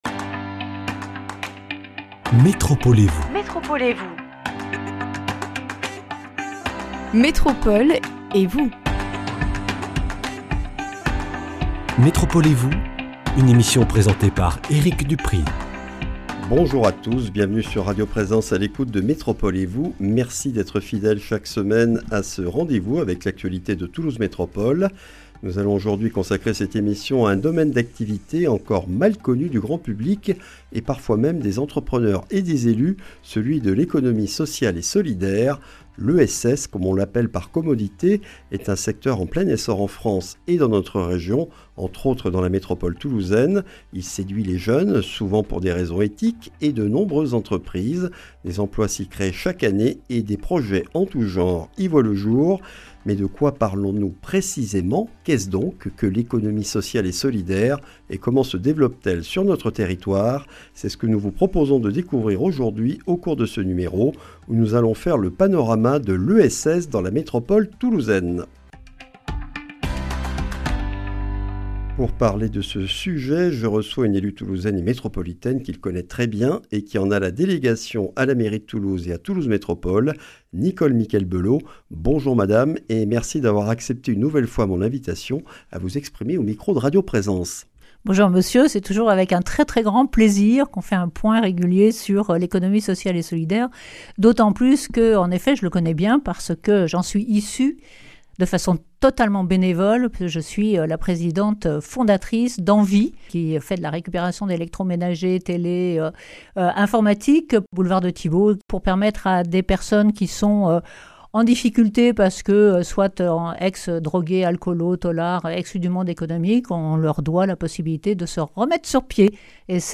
Nous faisons un tour d’horizon du secteur de l’économie sociale et solidaire (ESS) dans la métropole toulousaine avec Nicole Miquel-Belaud, conseillère municipale déléguée à la Mairie de Toulouse et membre du bureau de Toulouse Métropole, chargée de l’ESS. Nous présentons aussi le festival Toulouse Nouvelle Mode dont la 2e édition a lieu les 14 et 15 novembre aux Halles de la Cartoucherie.